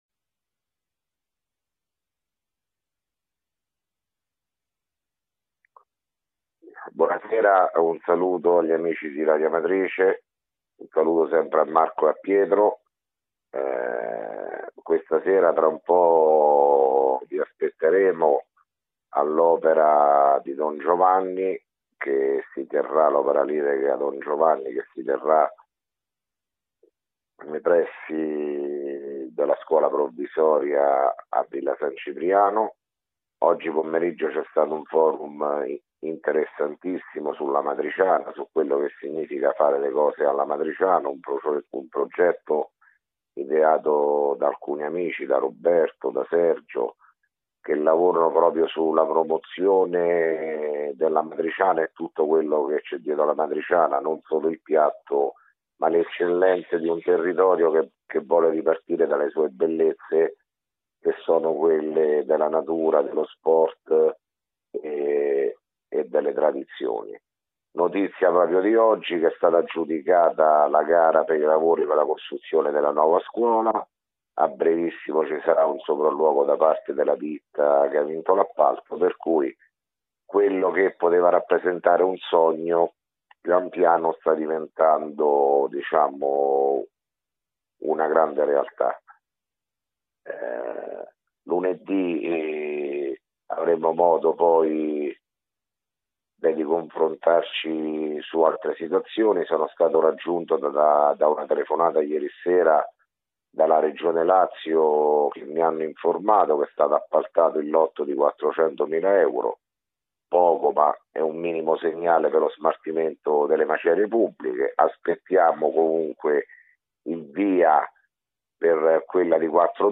Di seguito il messaggio audio del Sindaco Sergio Pirozzi dell’ 8 LUGLIO 2017: